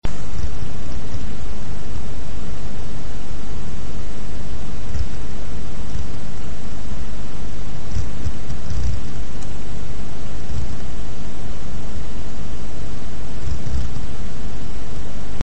Шумит усилитель в муз.центре
Но всё хорошее когда-нибудь кончается и зашумел у меня центр sad причем на один левый канал.
Симптомы: звук хаотичный, будто контакт плохой, шуршит, щелкает, с громкостью не связан, т.е. что на громкости 1 его слышно, что на громкости max его слышно точно так же.
Есть запись этого шуршания, правда записывалось на телефон и рядом комп шумел, но различить можно.